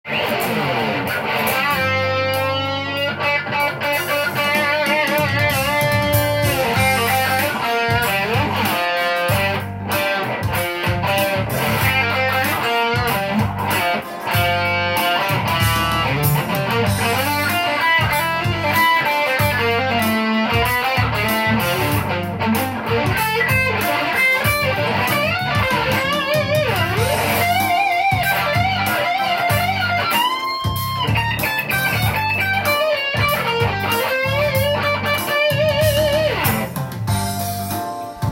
衝撃的な音の伸びと弾きやすさ！２割ほど上手そうに聞かせてくれます。